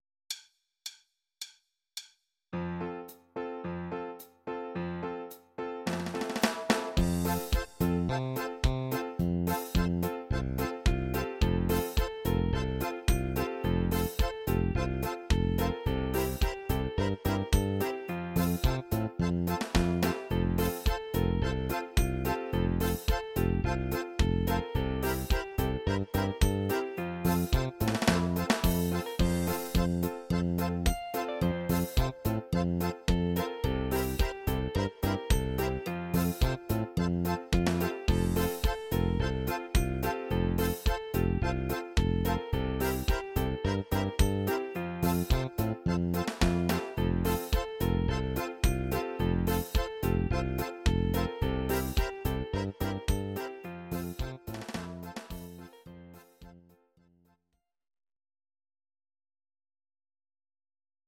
Audio Recordings based on Midi-files
Pop, Dutch, 1990s